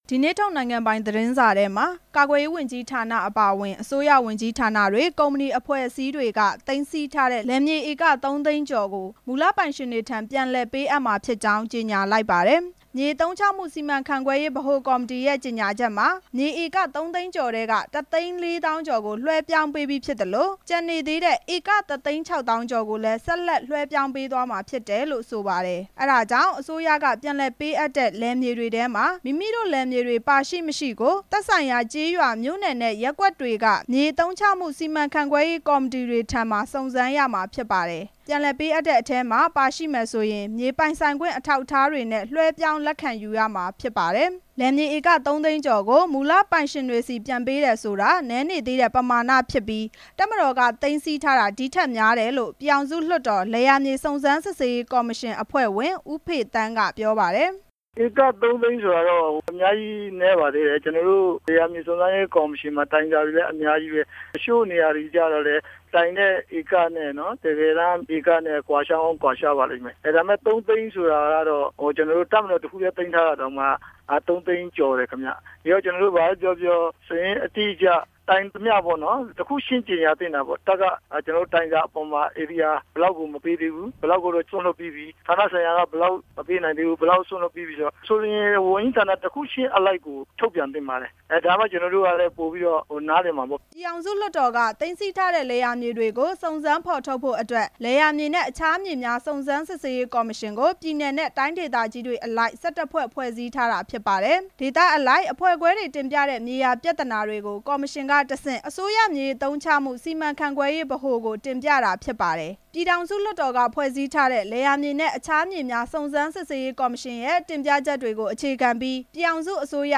ဒါပေမဲ့  လွှတ်တော်က တင်ပြချက်နဲ့ ကွဲလွဲနေတယ်လို့ ပြည်ထောင်စု လွှတ်တော် လယ်ယာမြေ စုံစမ်းစစ်ဆေးရေး ကော်မရှင် အဖွဲ့ဝင် ဦးမင်းသူ က ပြောပါတယ်။